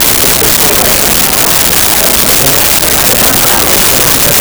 Bar Crowd 03
Bar Crowd 03.wav